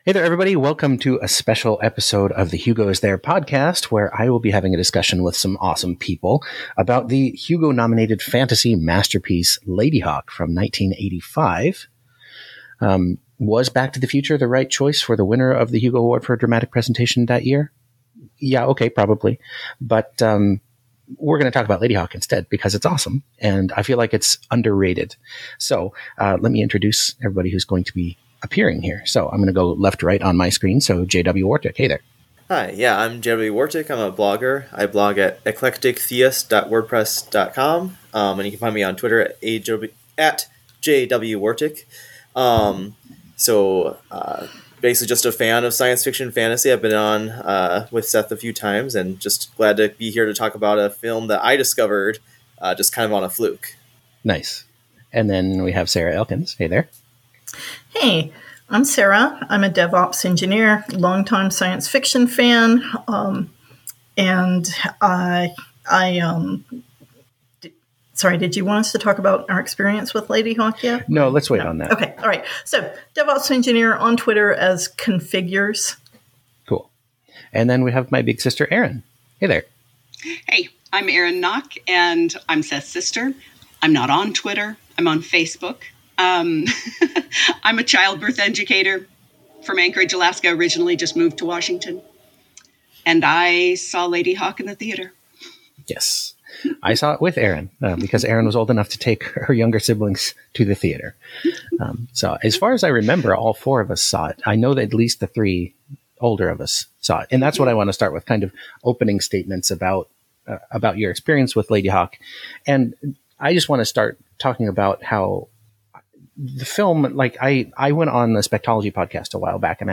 bonus-episode-ladyhawke-1985-film-discussion-panel.mp3